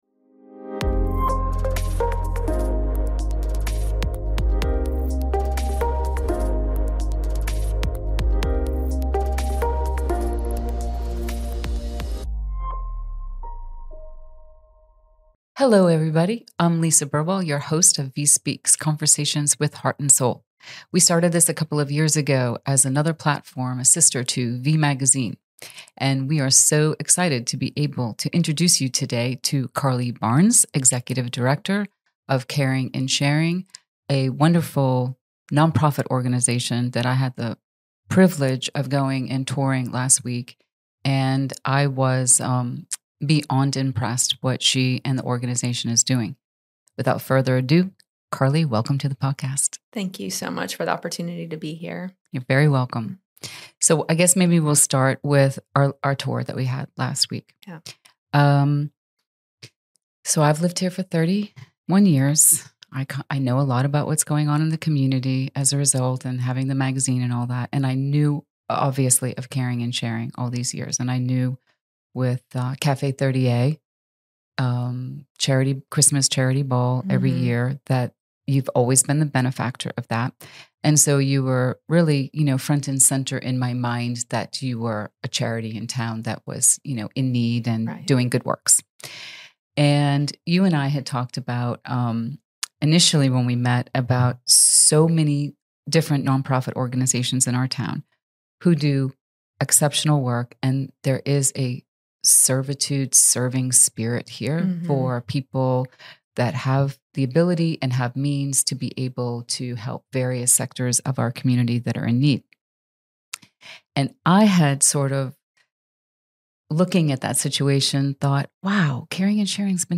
Tune in for a heart-centered conversation and an inside look into what it looks like to serve on the daily.